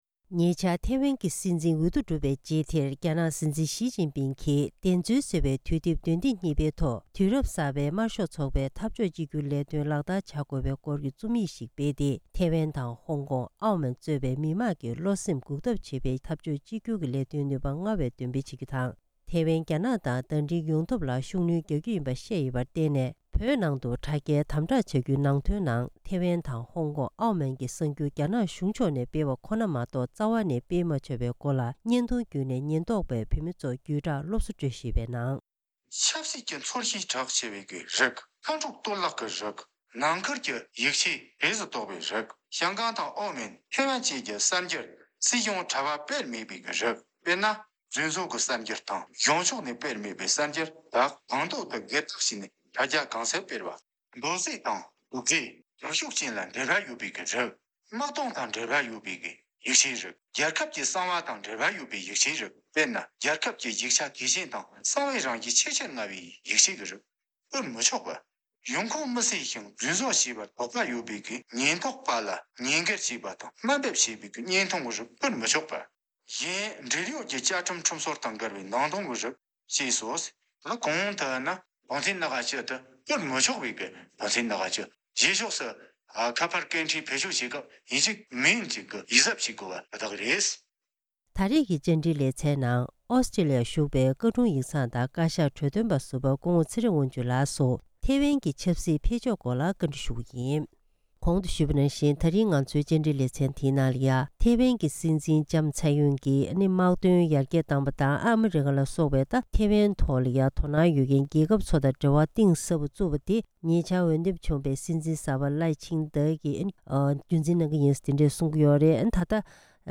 ད་རེས་ཐེ་ཝན་གྱི་སྲིད་འཛིན་གསར་པ་འོས་འདེམས་བྱུང་སྟངས་དེ་ཡིས་ཐེ་ཝན་མི་དམངས་ཀྱིས་རྒྱ་ནག་ཐོག་ལ་འཛིན་པའི་ལྟ་ཚུལ་ལ་འགྱུར་བ་ཕྱིན་ཡོད་མེད་སོགས་ཀྱི་སྐོར་ལ་བཀའ་འདྲི་ཞུས་པ་ཞིག་གསན་རོགས་གནང་།